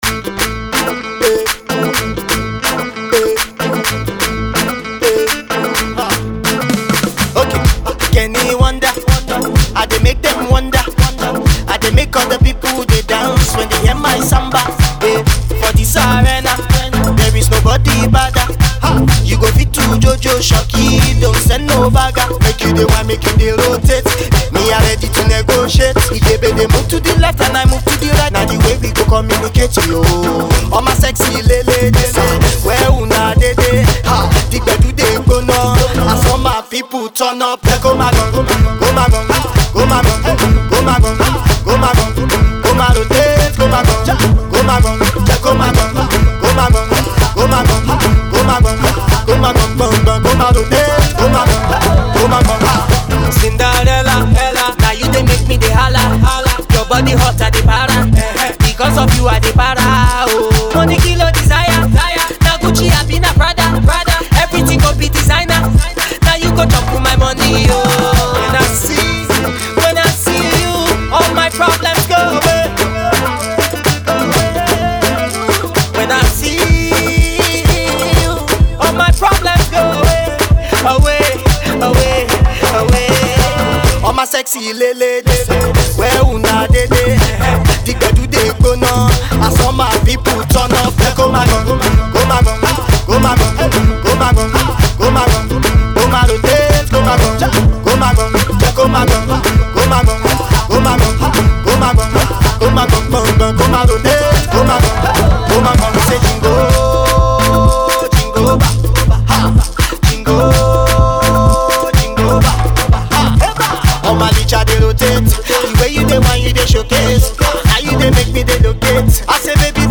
Pop song